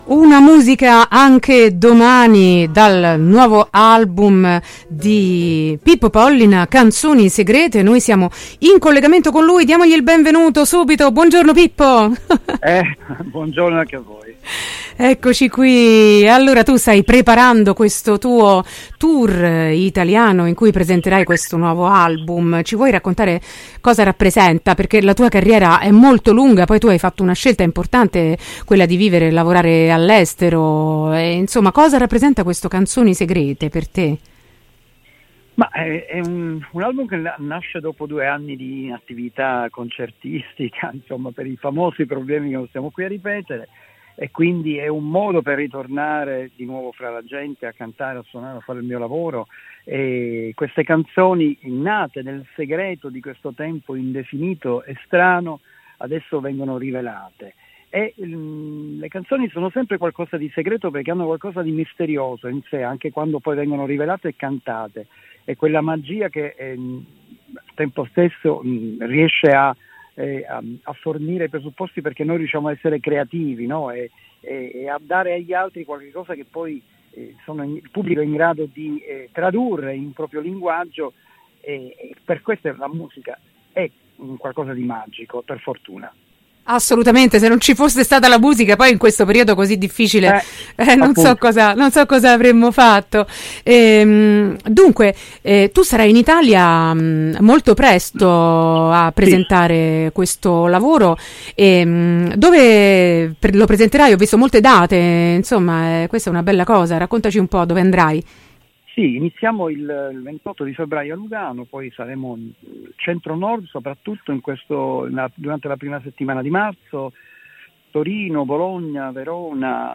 “Canzoni segrete”: intervista a Pippo Pollina
intervista-pippo-pollina-18-2-22.mp3